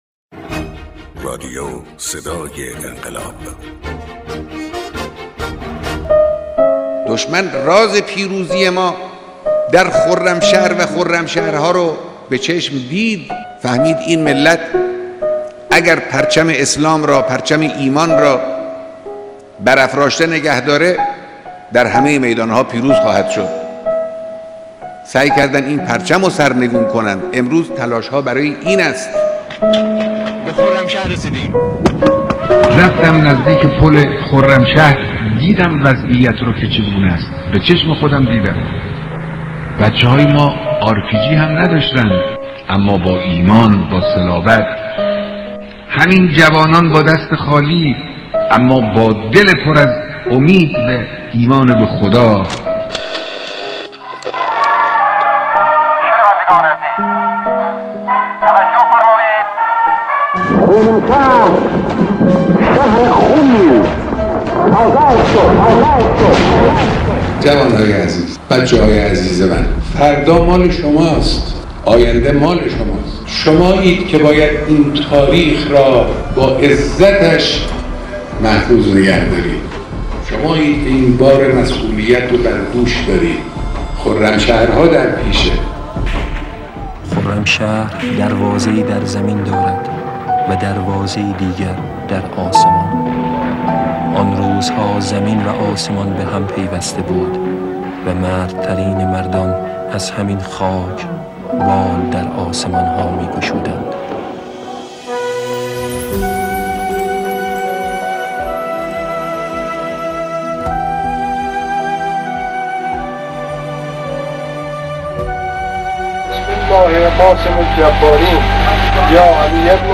بیانات مقام معظم رهبری درباره آزادی خرمشهر